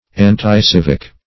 Anticivic \An`ti*civ"ic\ ([a^]n`t[i^]*c[i^]v"[i^]k), n.